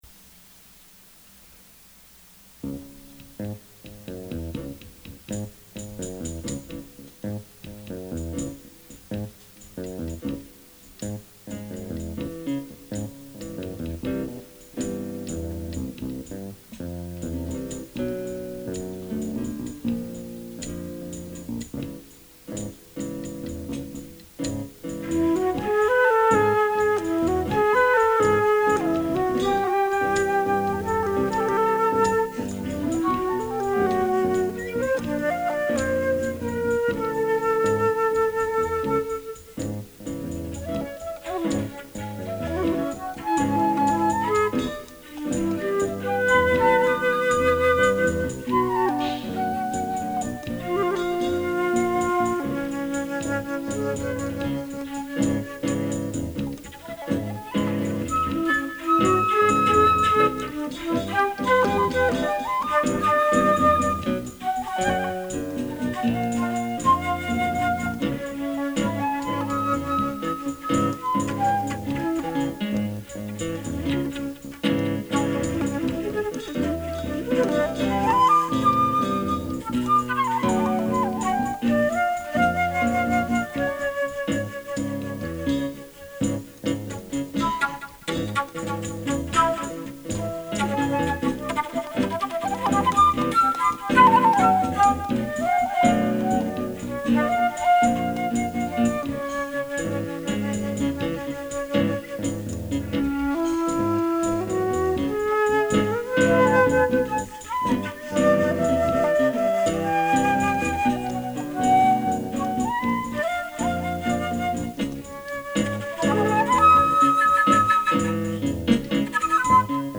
sorry about these recordings, they are strictly songwriter's product, little production or ochestration beyond what I could do with a keyboard and flute
this one is interlude or theme music idea: